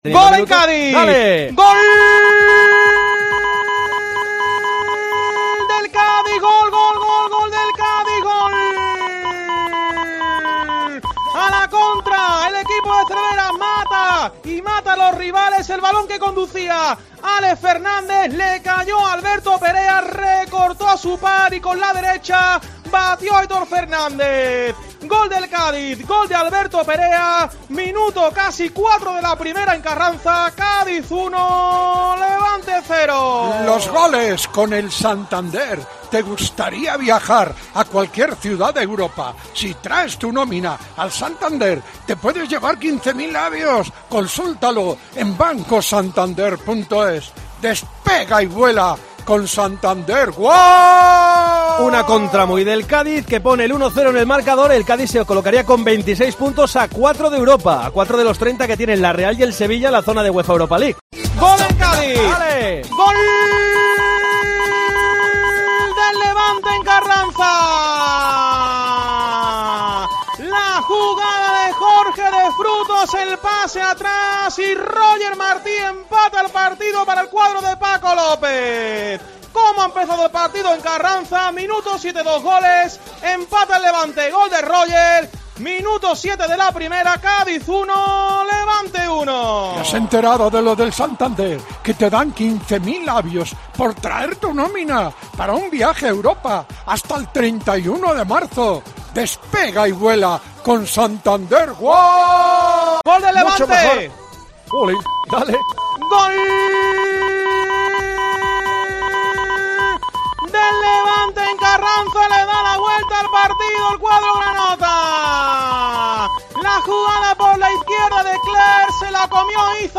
Los goles del Cádiz - Levante en Tiempo de Juego (2-2)